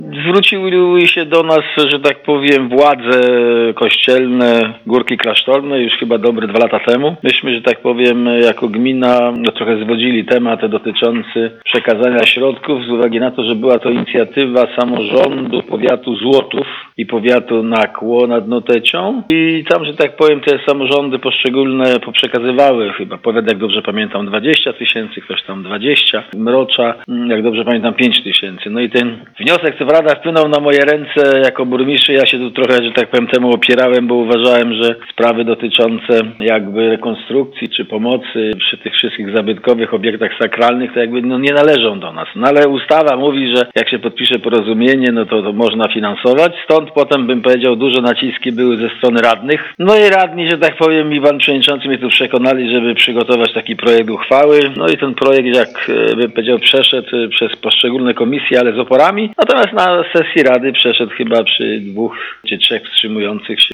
O tym skąd wziął się pomysł darowizny powiedział nam Ignacy Pogodziński, burmistrz Szubina.